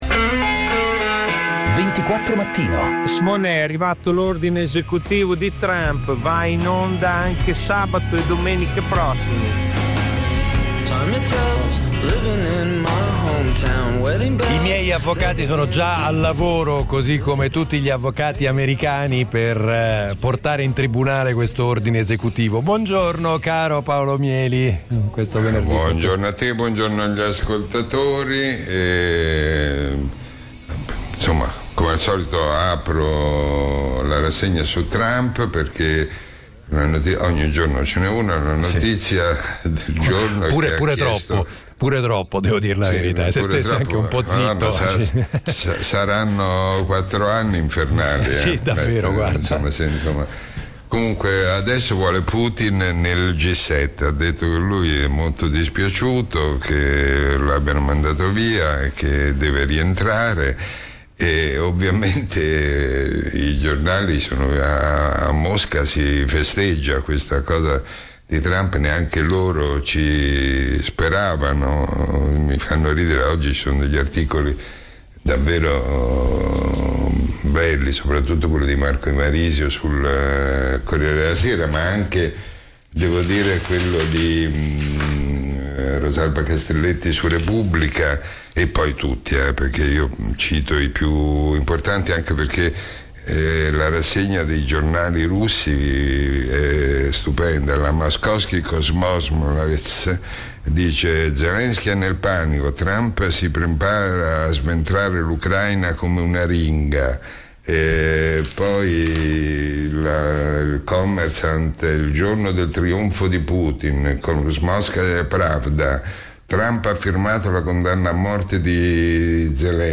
Prima l'imperdibile appuntamento quotidiano con Paolo Mieli per commentare i fatti della giornata. Poi gli ospiti dal mondo della politica, dell'economia, della cronaca, della giustizia: i protagonisti dei fatti o semplicemente chi ha qualcosa di interessante da dire o su cui riflettere, compresi gli ascoltatori.